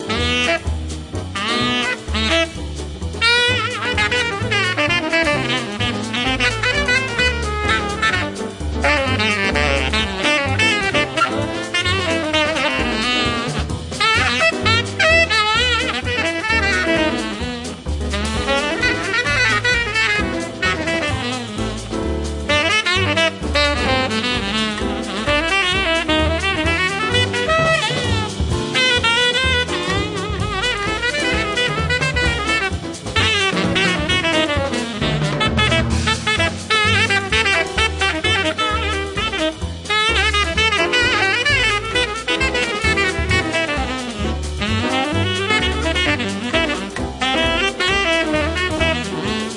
The Best In British Jazz
Recorded Clowns Pocket Studio, June 2002